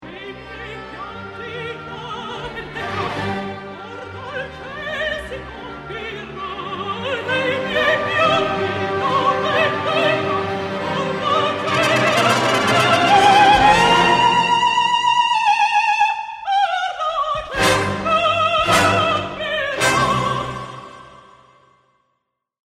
mezzosoprano